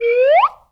whistle_slide_up_01.wav